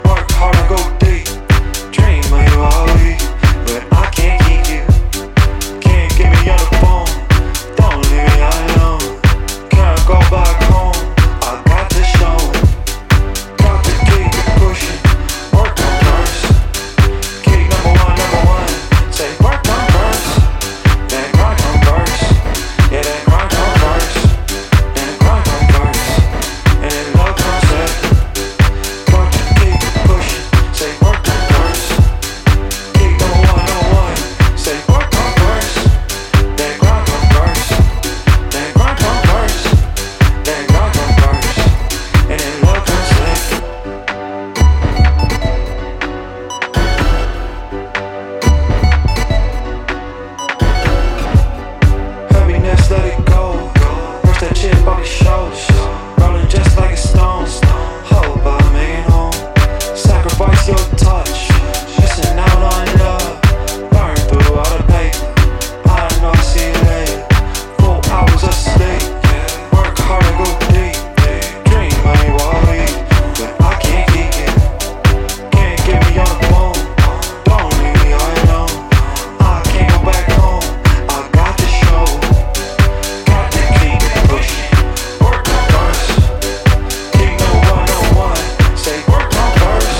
ねっとりとしたヴォーカルと弾むオルガンが絡む